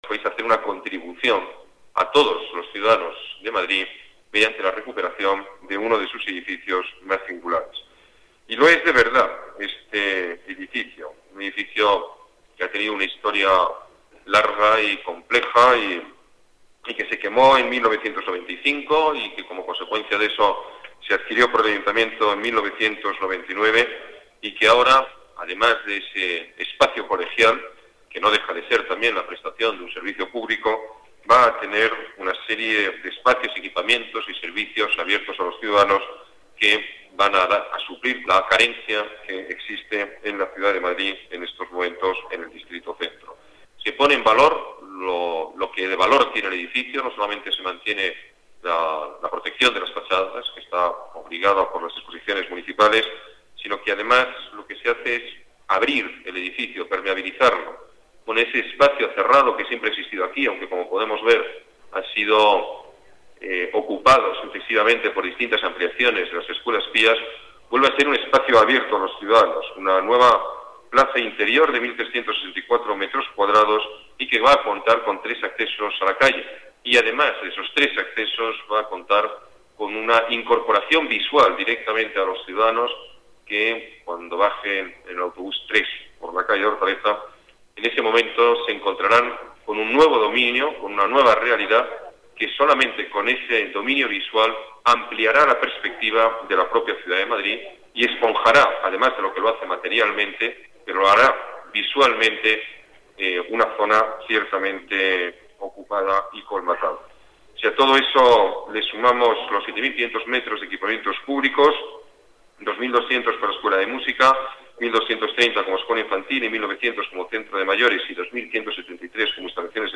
Nueva ventana:Declaraciones Alberto Ruiz-Gallardón: Remodelación